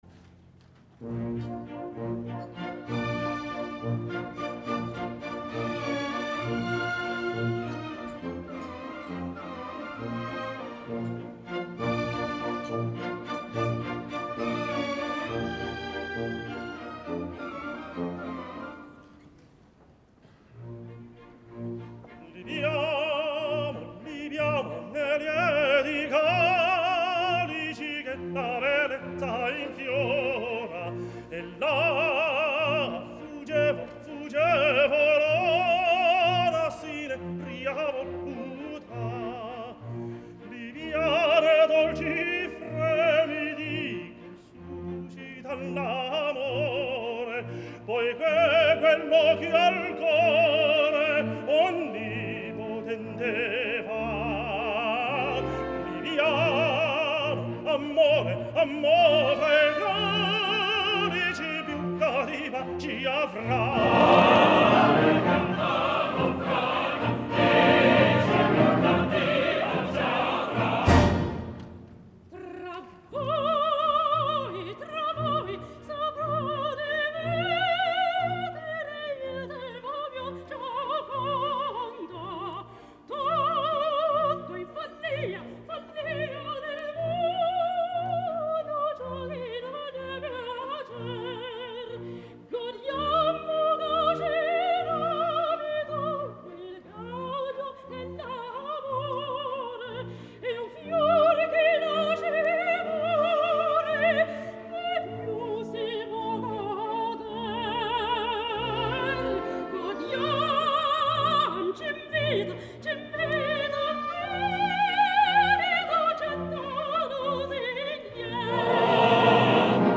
意大利语演唱